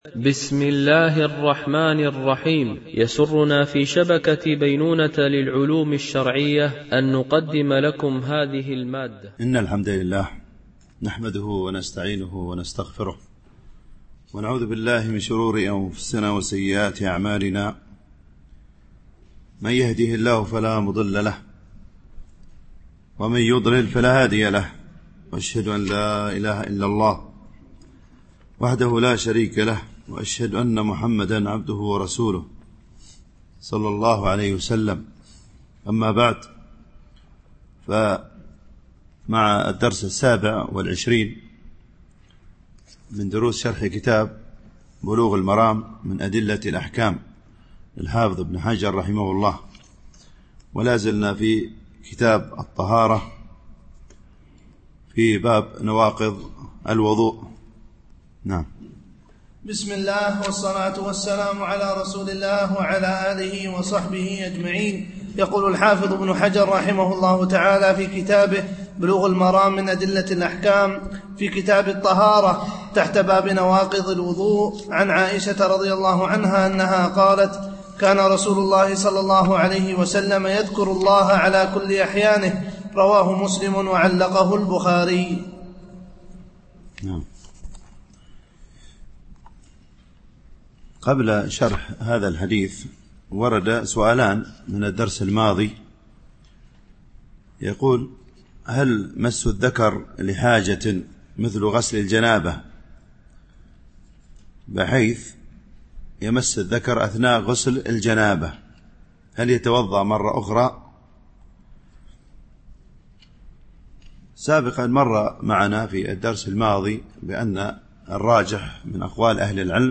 شرح بلوغ المرام من أدلة الأحكام - الدرس 27 ( كتاب الطهارة - باب نواقض الوضوء، الحديث 73)